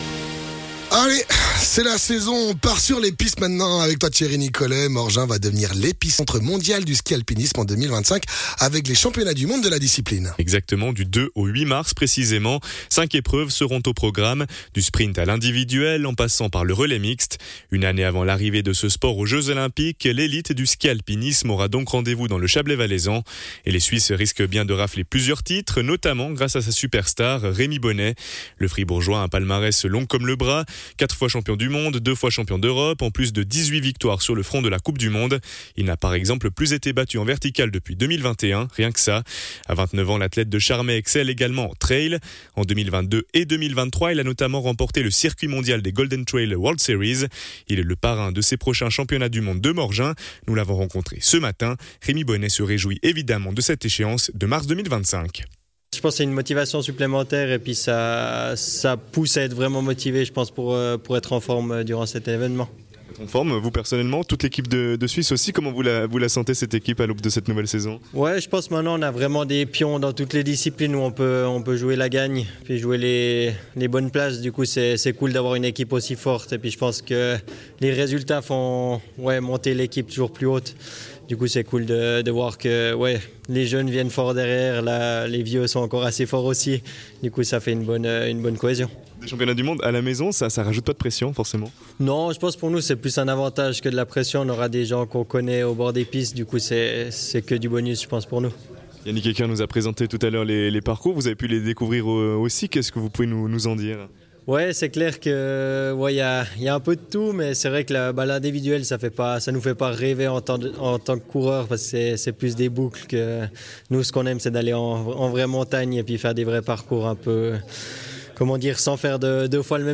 Intervenant(e) : Rémi Bonnet, multiple champion du monde